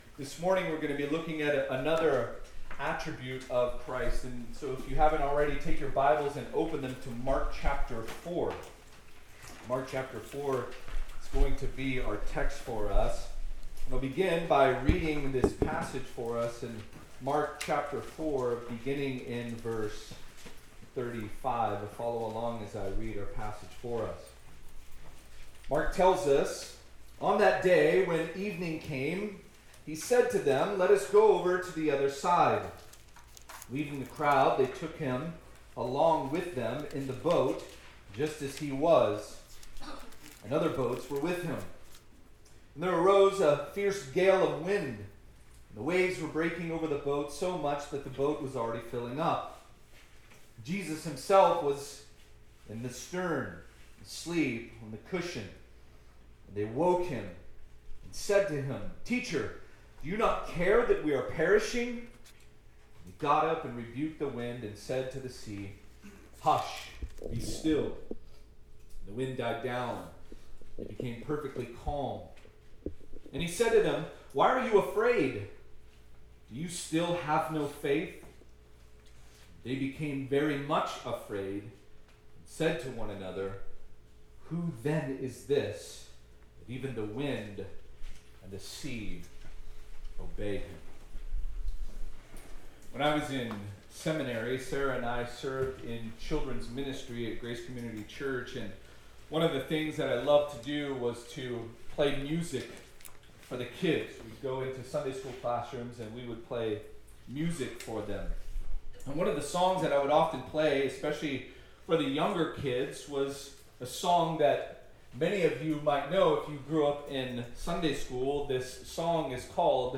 College/Roots Roots Summer Retreat 2025 - On the Shores of Galilee Audio ◀ Prev Series List Next ▶ Previous 1.